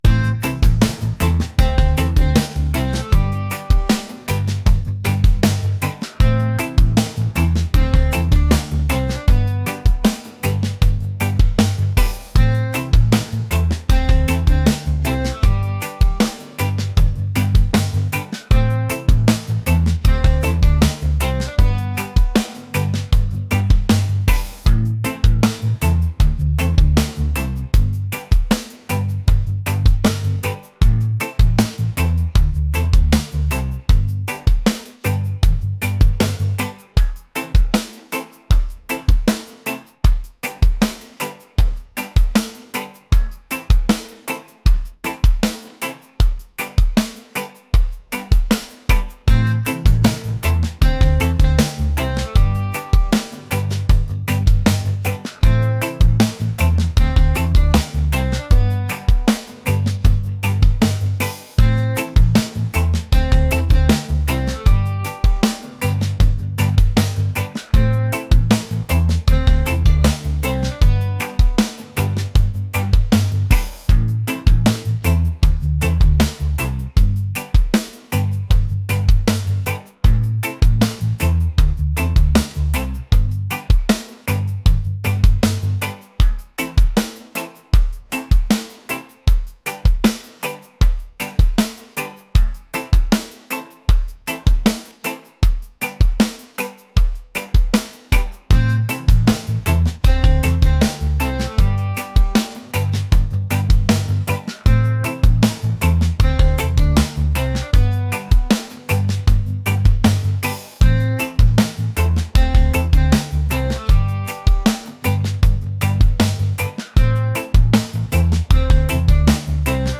laid-back | reggae | groovy